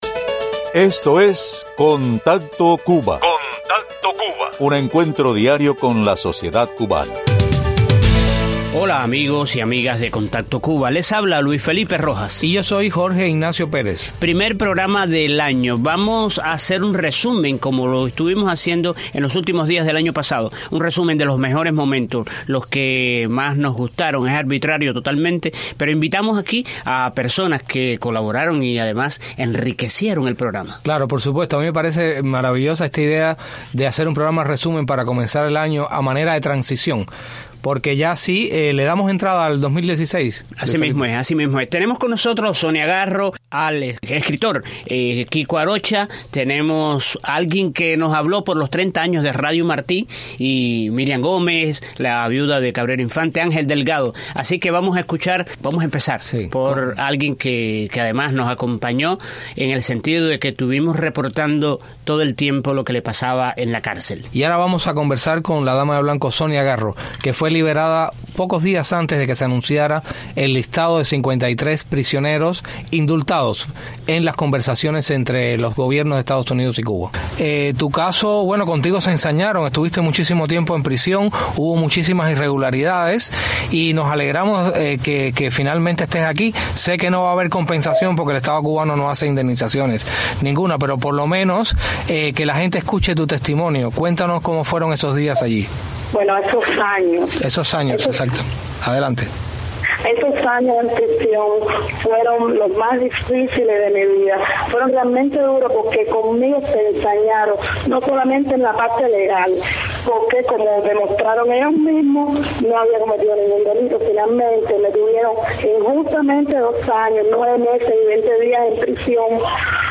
Con esta colección de entrevistas a cubanos de “las dos orillas”, recordamos algunos de los mejores momentos del 2015.